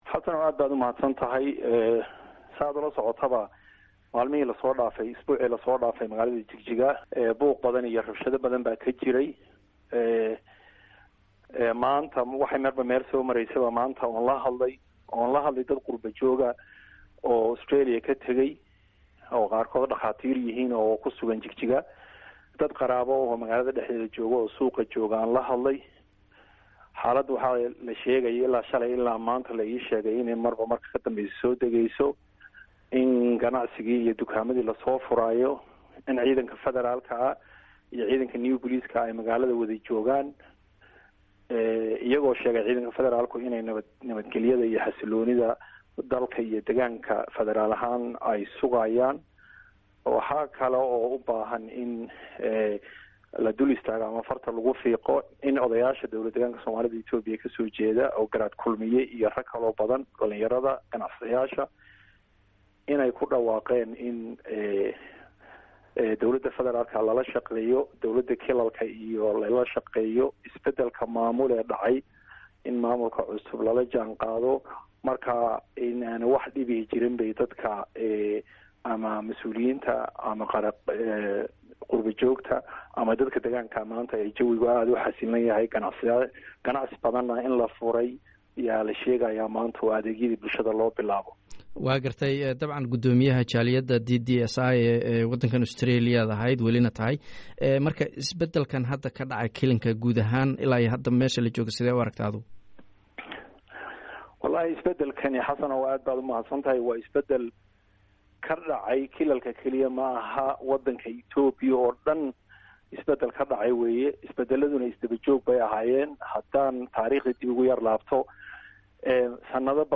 Waraysi: Gudoomiyaha DDSI